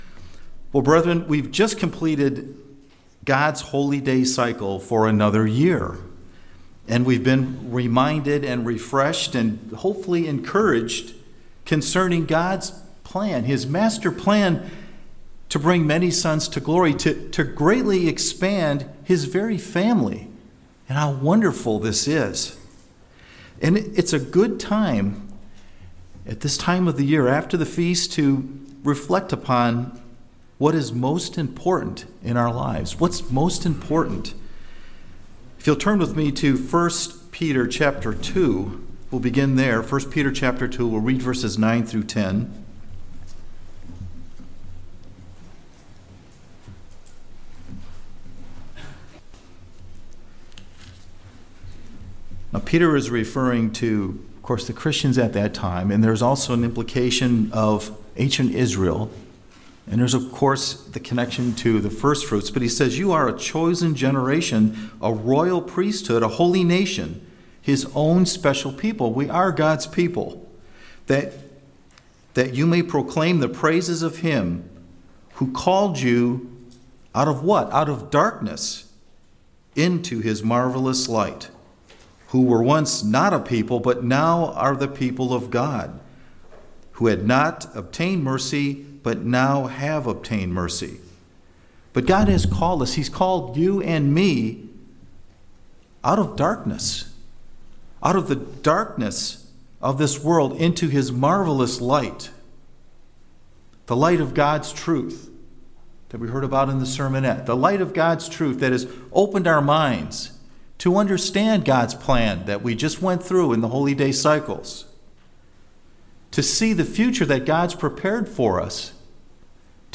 So what does it mean to walk worthy of our calling? This sermon covers seven elements associated with walking appropriately.